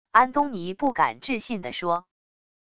安东尼不敢置信地说wav音频